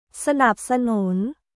サナップ・サヌン